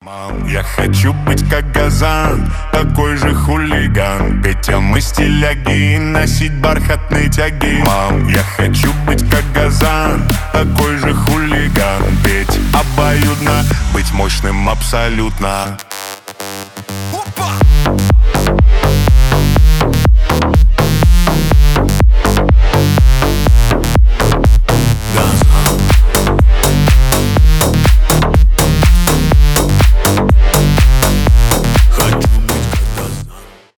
Клубные рингтоны
зажигательные
веселые